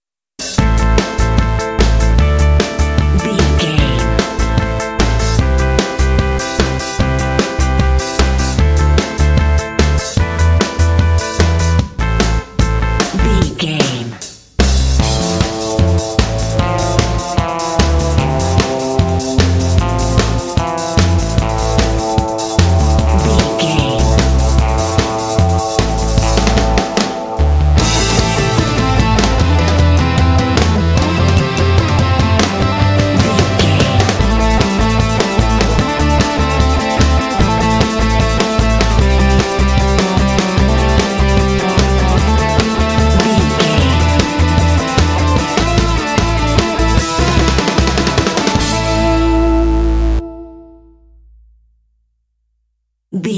This cool, driving track is great for action games.
Aeolian/Minor
Fast
driving
bouncy
groovy
bright
electric guitar
bass guitar
drums
rock
alternative rock